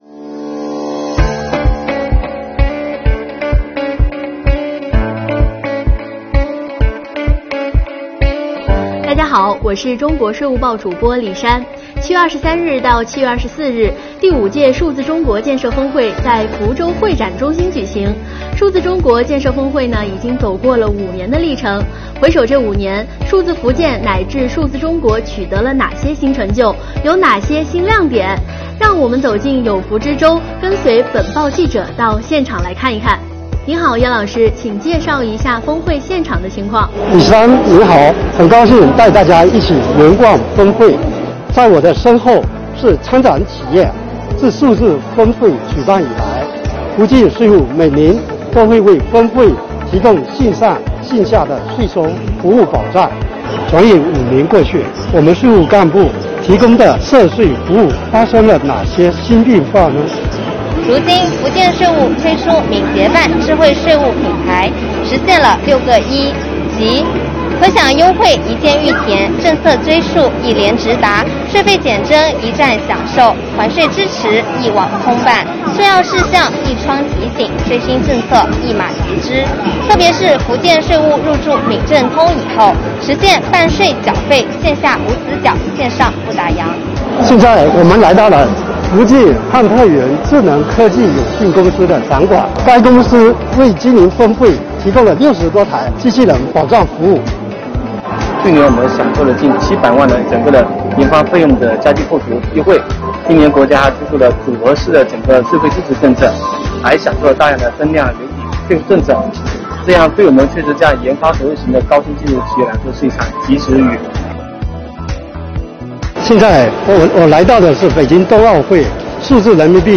让我们走进有福之州，跟随本报记者到现场看一看。